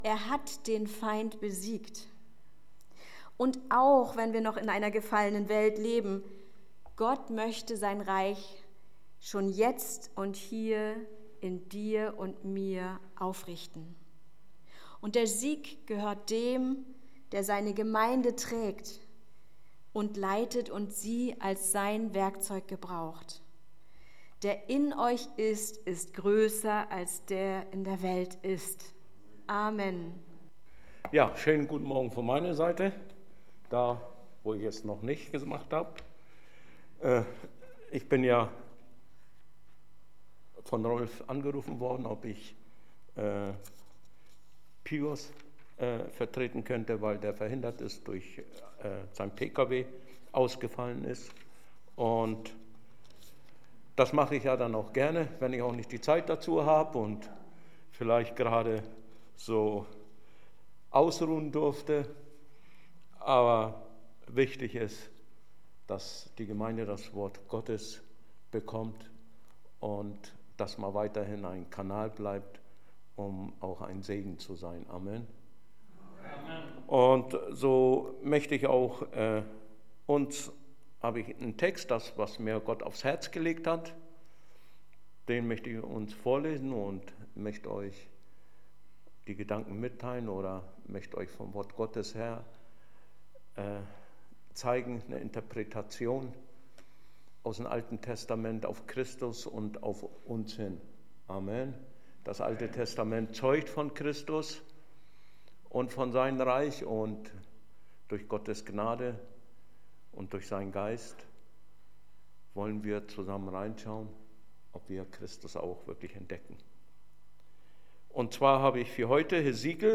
September 2024 Wasser des Lebens Teil I Prediger